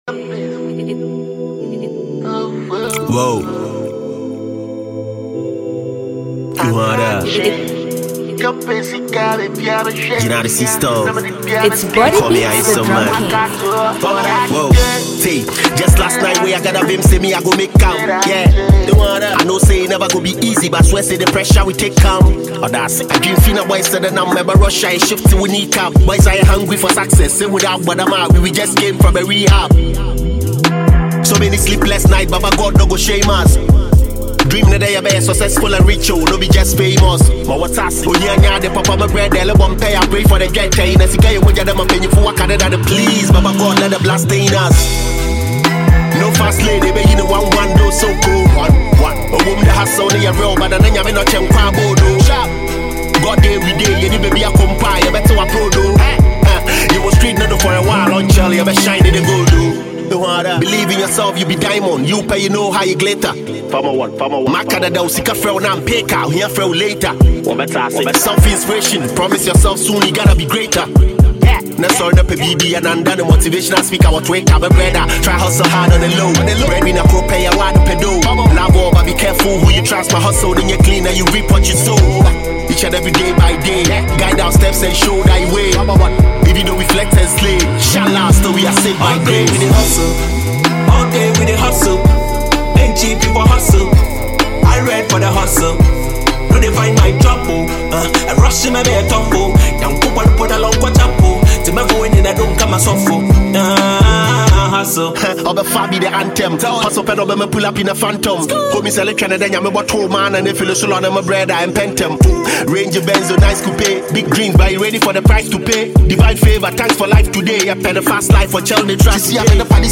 Talented Ghanaian rapper